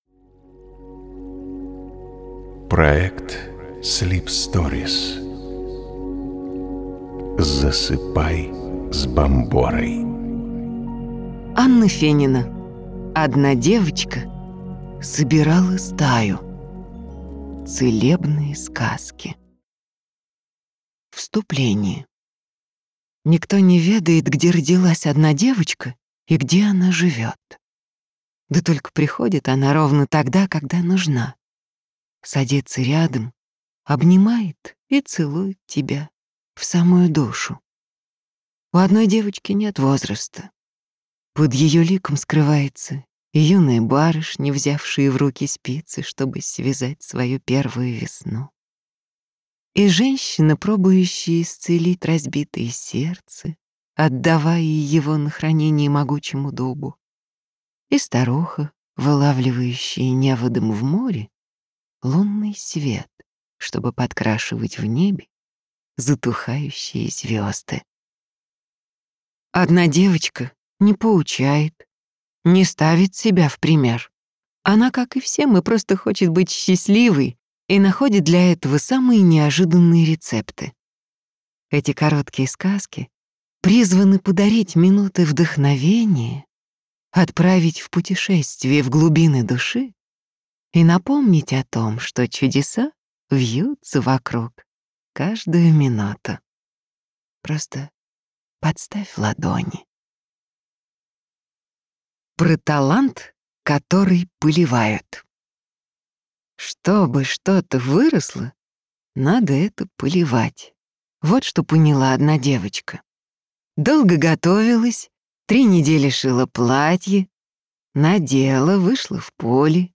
Аудиокнига Одна девочка собирала стаю | Библиотека аудиокниг